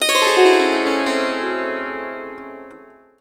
SITAR LINE11.wav